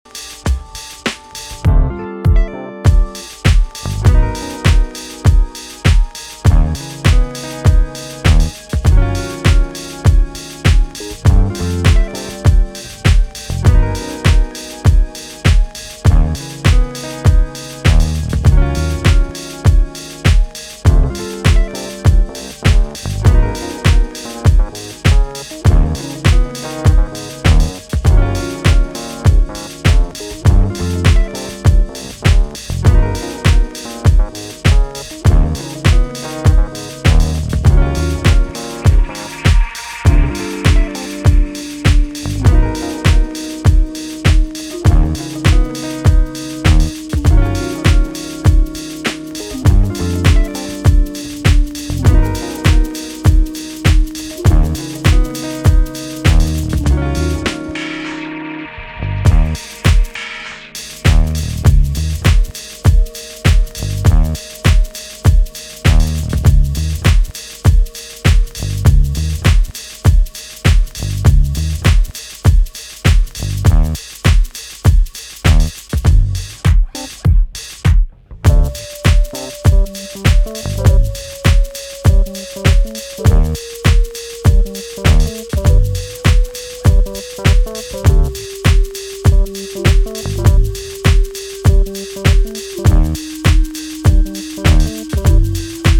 Do we like house records that could be on the wrong speed?